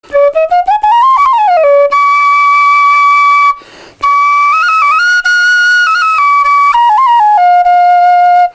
Звучание курая